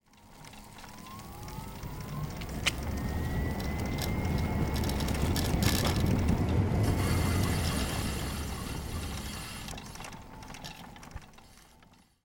E-Scooter Electric Bike - Outdoor Open Road - Start to FullSpeed to Stop -  Windy Ride Braking - Spot Tire Mics Only.wav